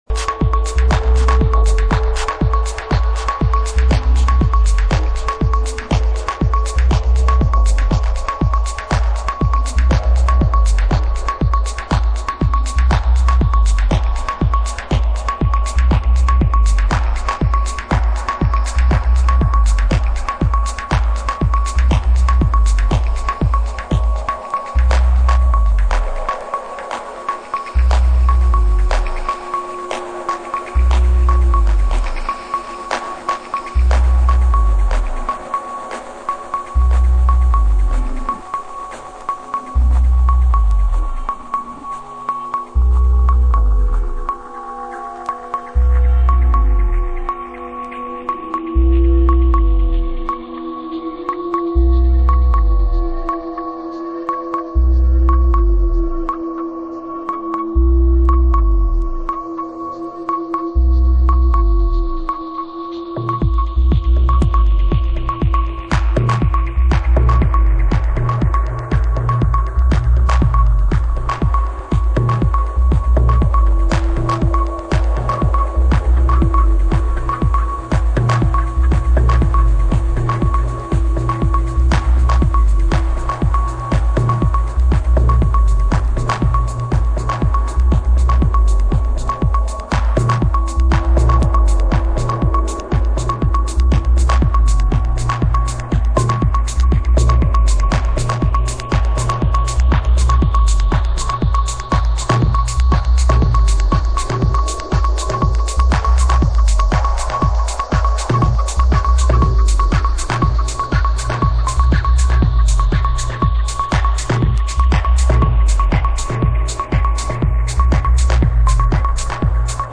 Deepest！！